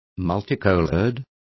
Complete with pronunciation of the translation of multicoloured.